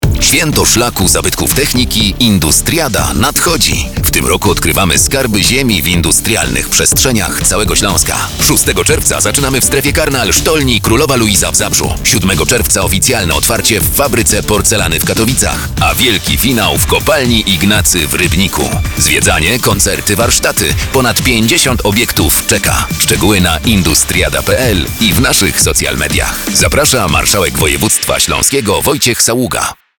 Spot radiowy
spot-radiowy-30sek.mp3